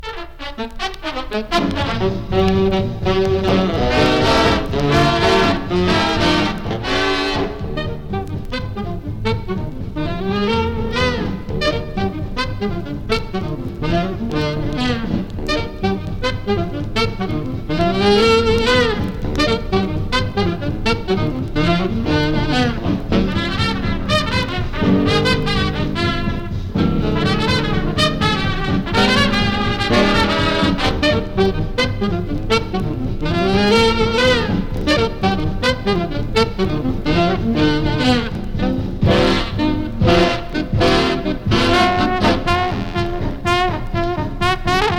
Jazz, Big Band, Swing　USA　12inchレコード　33rpm　Mono